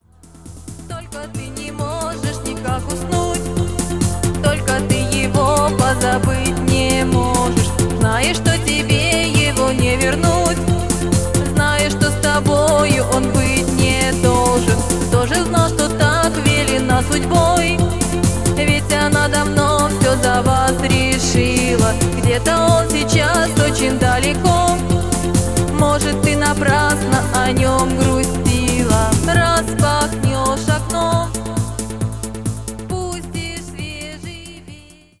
• Жанр: Танцевальная